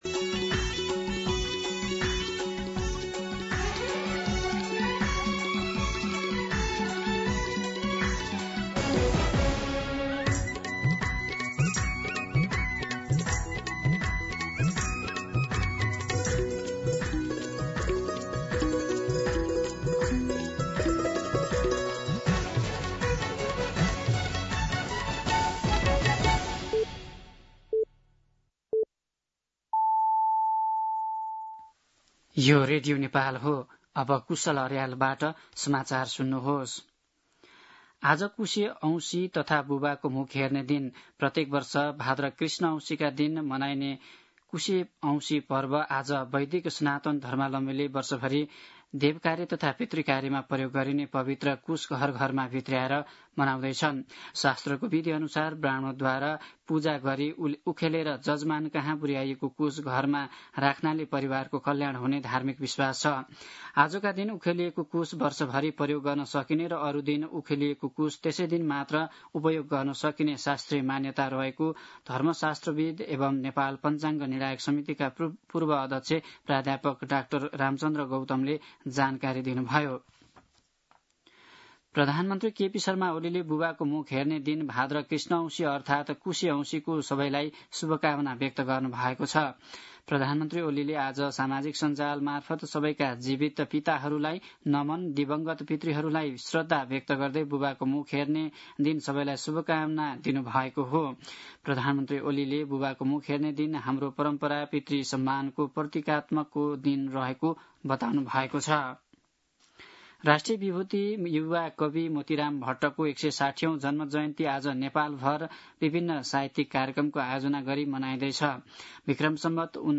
दिउँसो ४ बजेको नेपाली समाचार : ७ भदौ , २०८२
4pm-Nepali-News.mp3